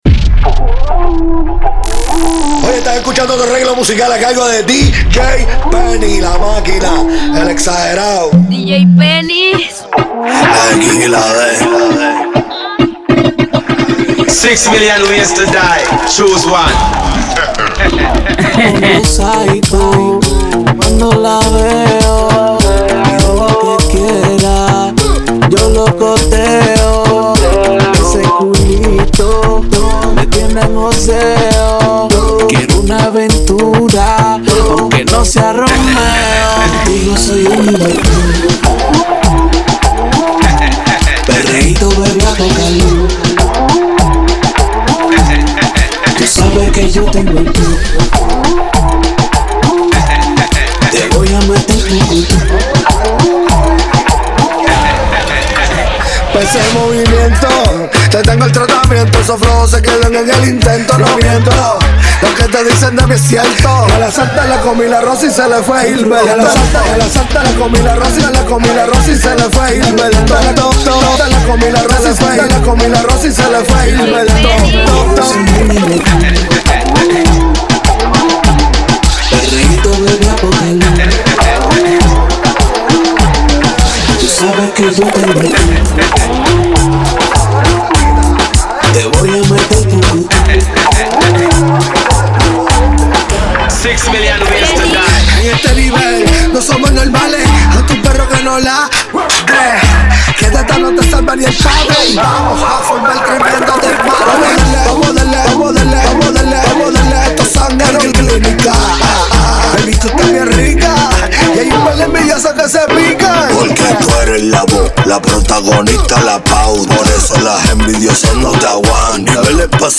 dúo de reggaetón